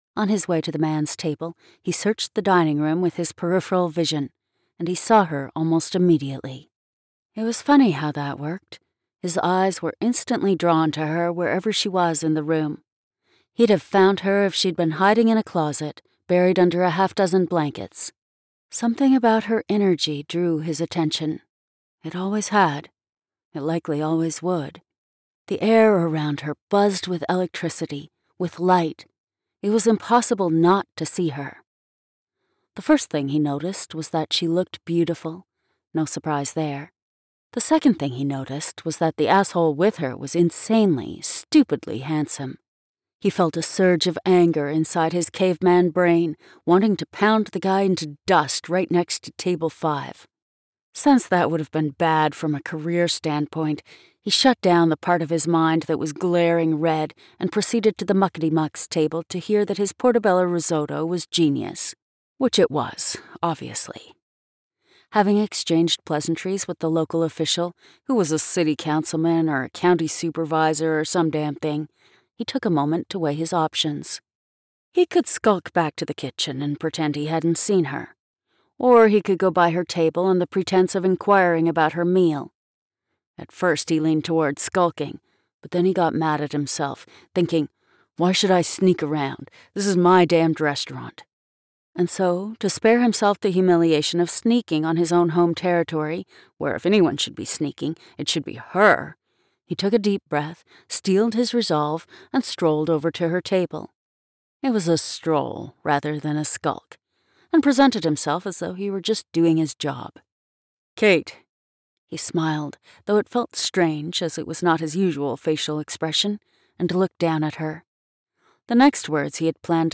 • Narrator: